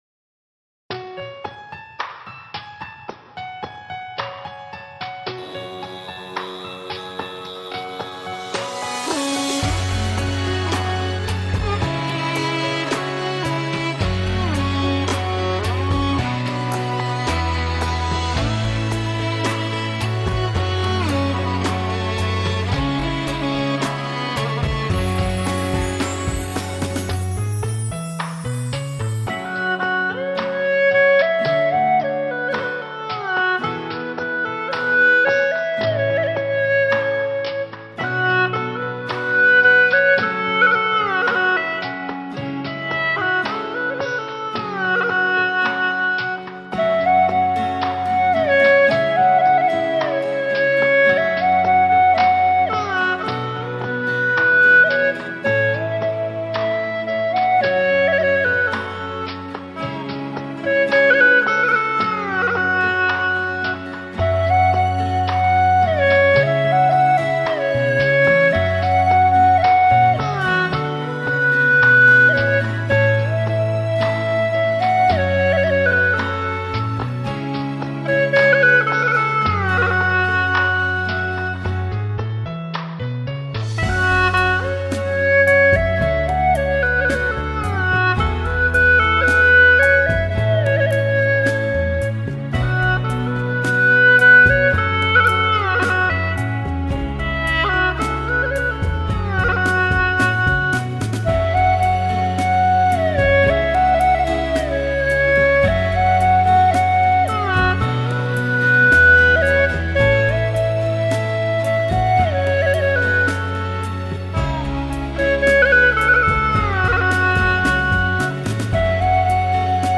调式 : A转降B 曲类 : 流行
又一首优美的草原歌曲